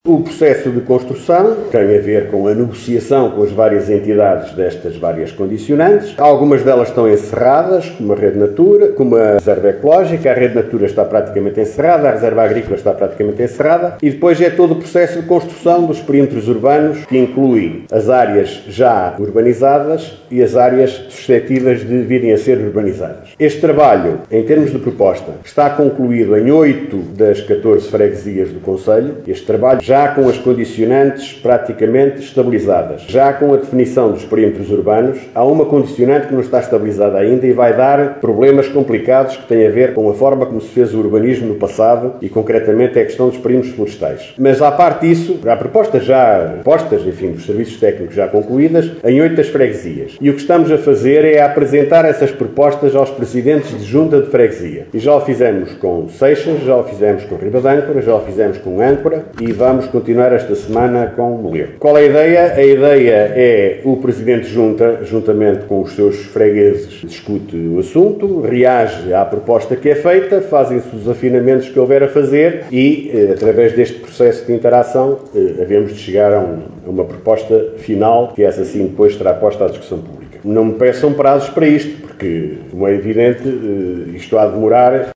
Guilherme Lagido explicou aos deputados eleitos para a Assembleia Municipal de Caminha, durante o último encontro daquele órgão autárquico, que as condicionantes com a Reserva Ecológica, Reserva Agrícola ou Rede Natura já estão, a maioria, definidas.